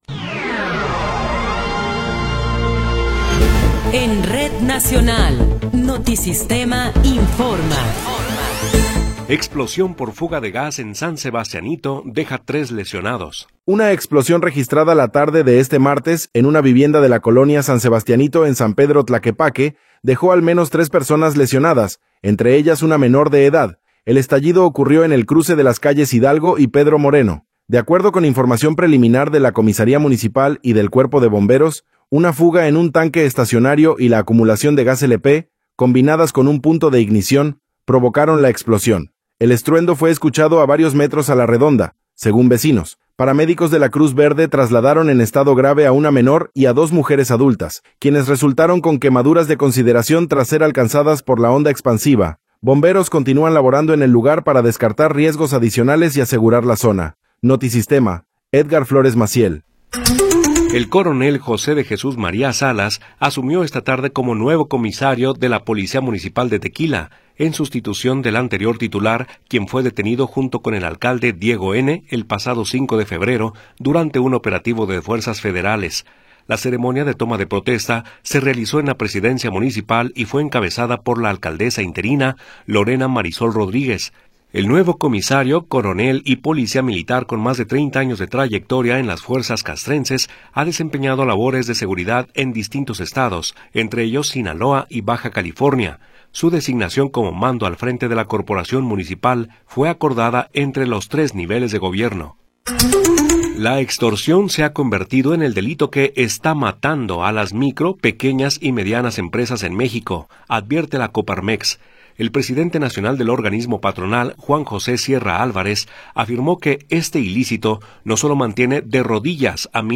Noticiero 18 hrs. – 3 de Marzo de 2026